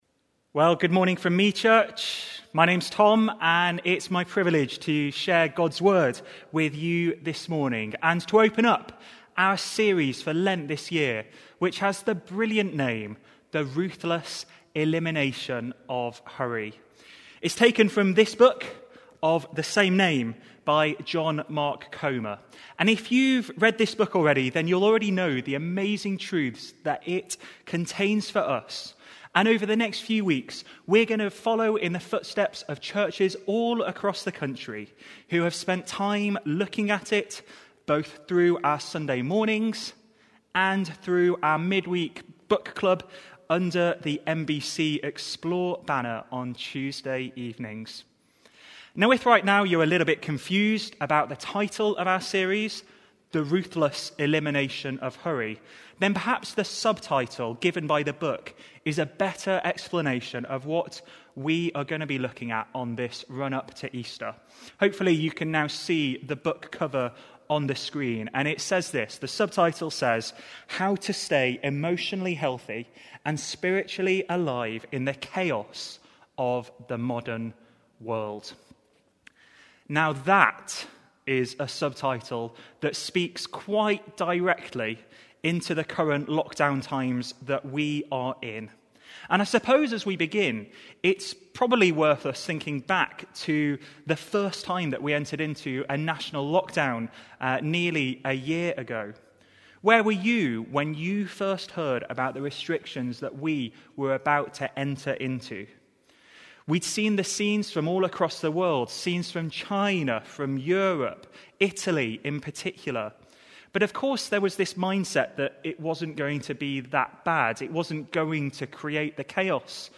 In light of this, we are inviting our congregation and community to join us live on Sundays online.